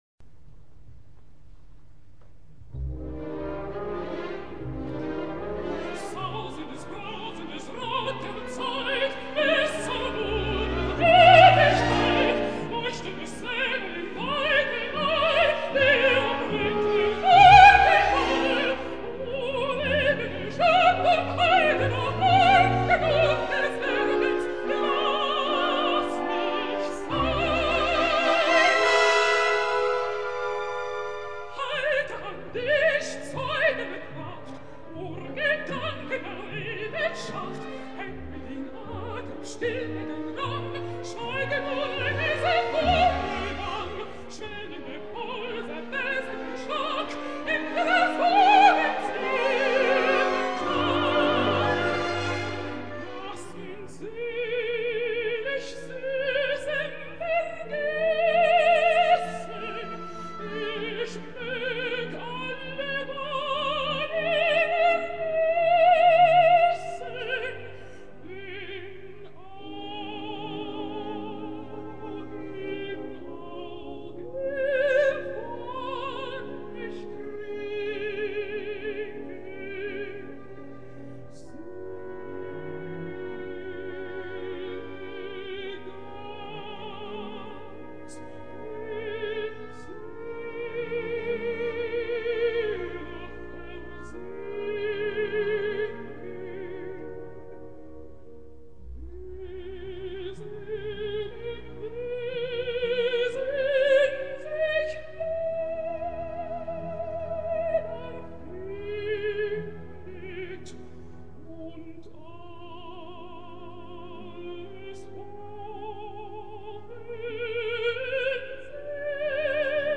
This entry was posted in Classical Music.